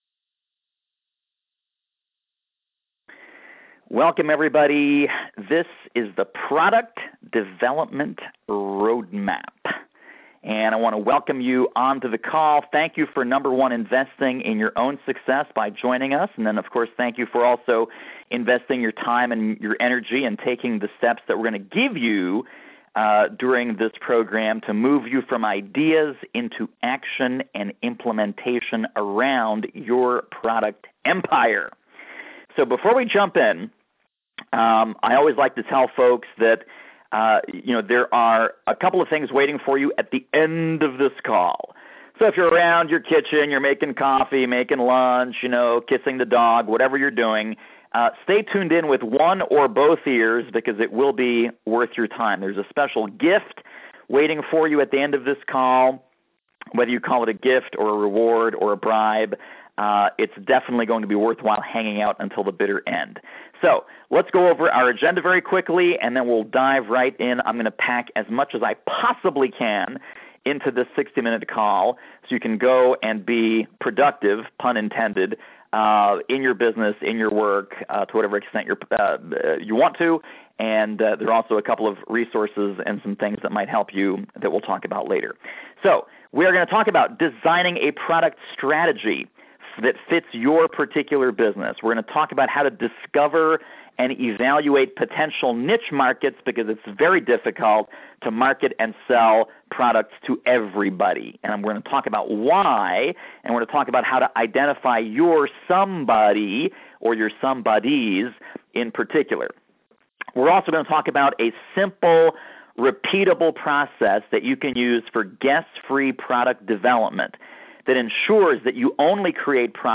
YOUR Product Development Roadmap. FREE Teleseminar